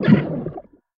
Sfx_creature_babypenguin_hold_unequip_under_01.ogg